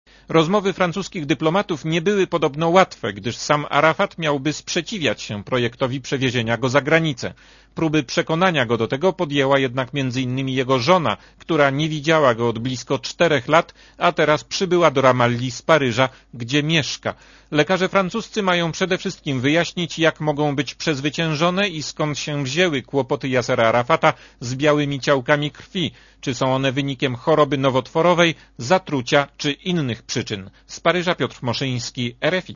arafat_w_paryzu.mp3